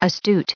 added pronounciation and merriam webster audio
1929_astute.ogg